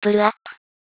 pull-up.wav